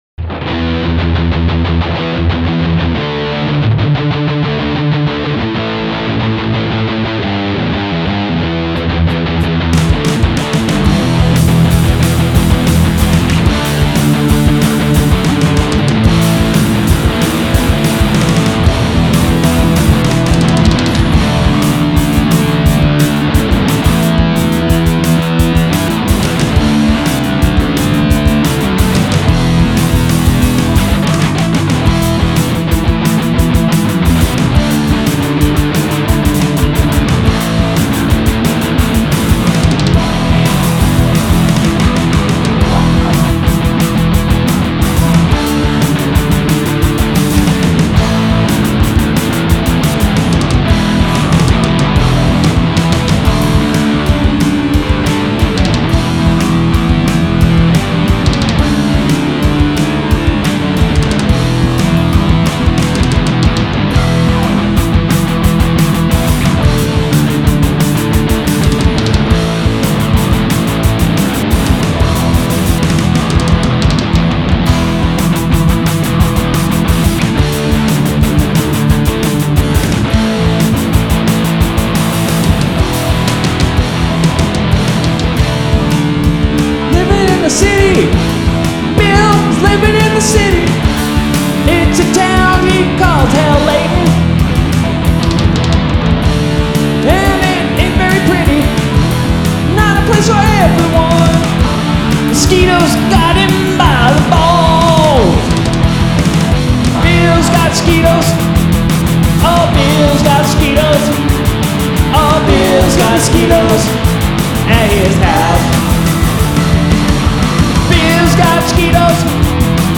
Rockin’ tune about watching the abatement truck drive on by…